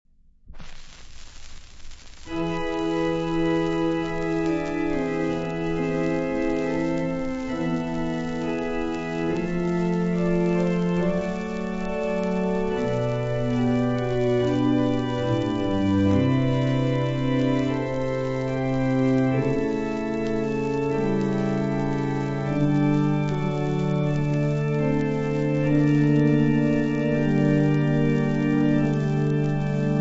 Personaggi e interpreti: organo ; Dupre', Marcel